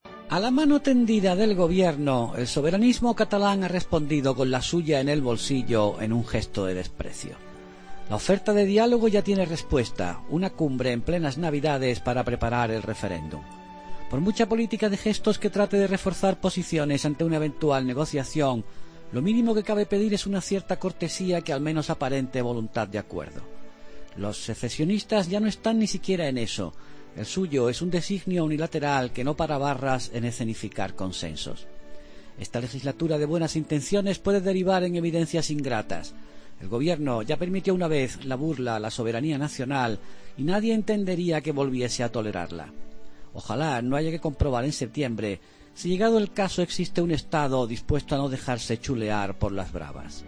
El análisis de Ignacio Camacho en 'La Linterna' sobre la convocatoria para el próximo 23 de diciembre de una cumbre para preparar el referéndum sobre la independencia en 2017 de Cataluña.